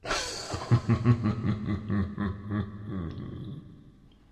Звуки дьявола